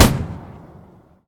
mg-shot-2.ogg